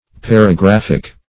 Search Result for " paragraphic" : The Collaborative International Dictionary of English v.0.48: Paragraphic \Par`a*graph"ic\, Paragraphical \Par`a*graph"ic*al\, a. Pertaining to, or consisting of, a paragraph or paragraphs.